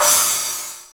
Index of /90_sSampleCDs/Sound & Vision - Gigapack I CD 1 (Roland)/CYM_CRASH mono/CYM_Crash mono
CYM CRA07.wav